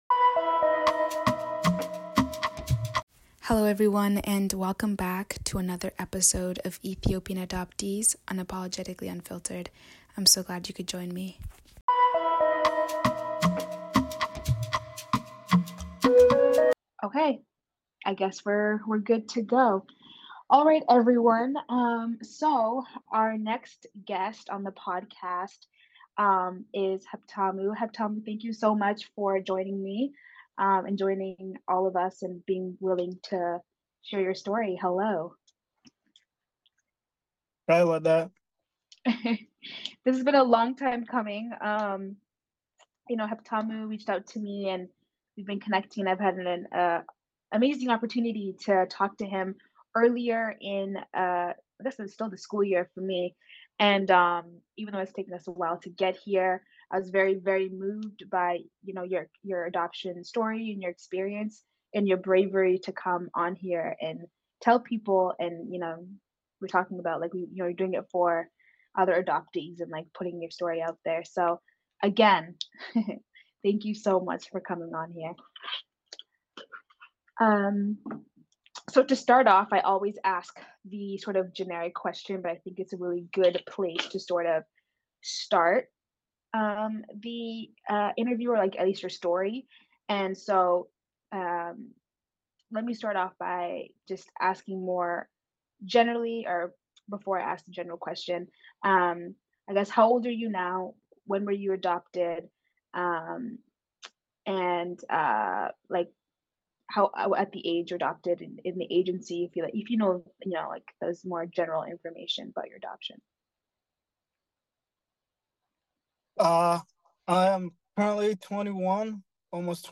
(Interview Part 1)